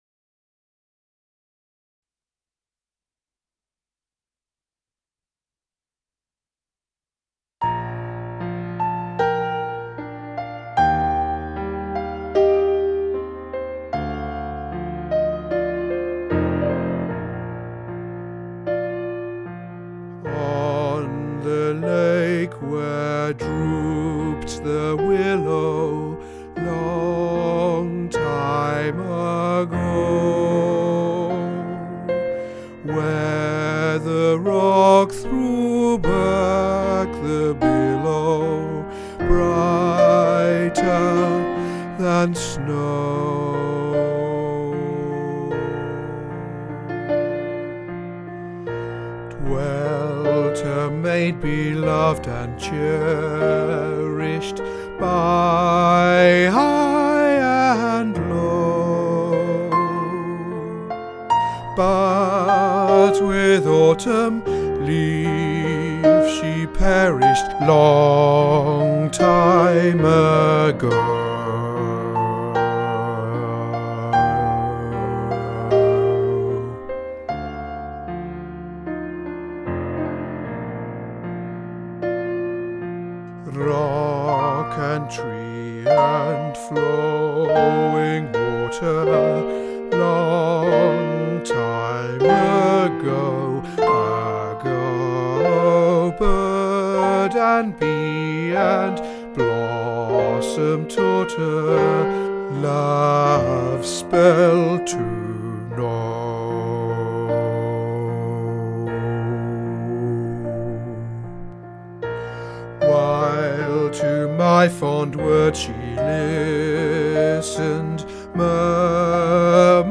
Long-Time-Ago-Alto | Ipswich Hospital Community Choir
Long-Time-Ago-Alto.mp3